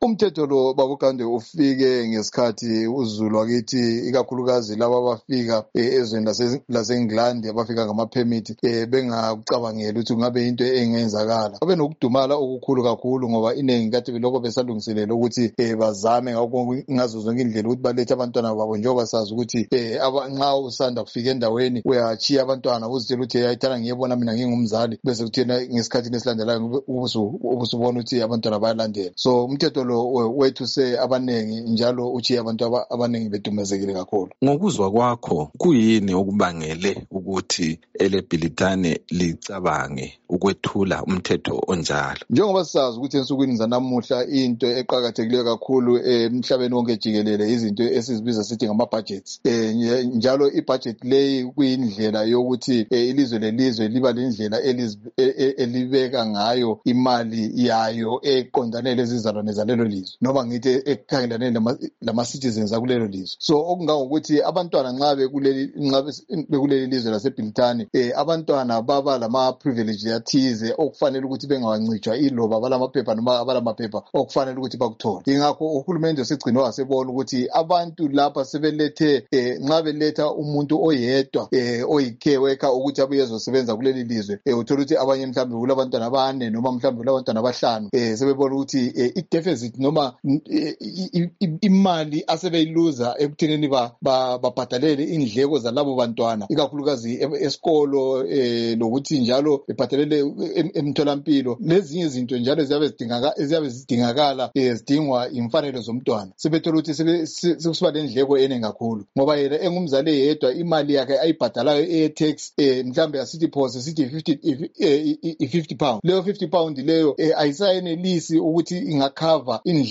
Ingxoxdo